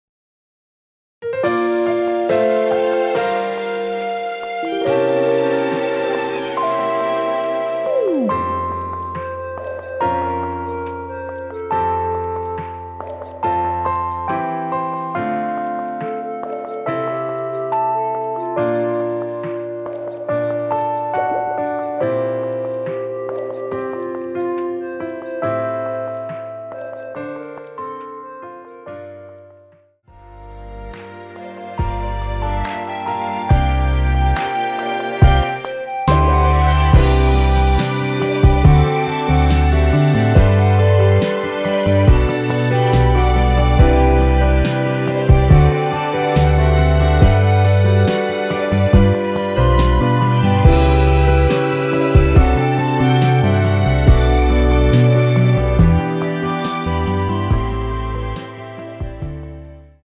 원키에서(+3)올린 멜로디 포함된 MR입니다.
앞부분30초, 뒷부분30초씩 편집해서 올려 드리고 있습니다.
(멜로디 MR)은 가이드 멜로디가 포함된 MR 입니다.